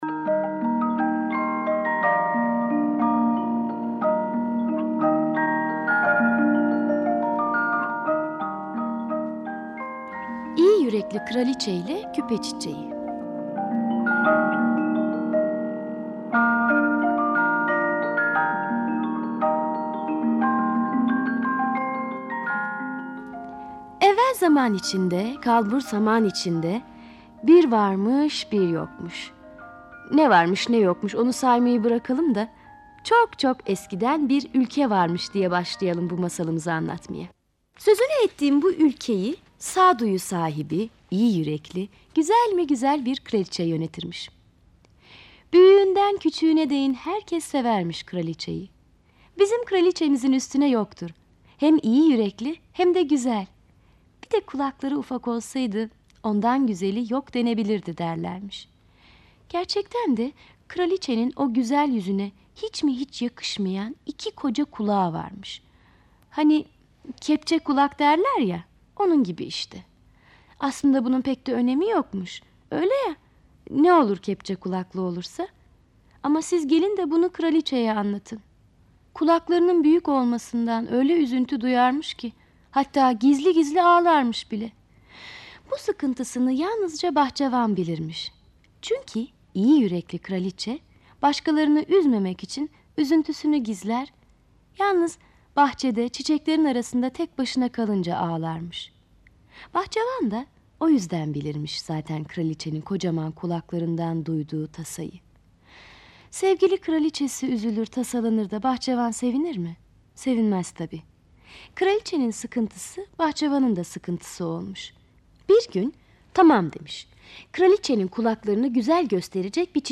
Kategori Sesli Çocuk Masalları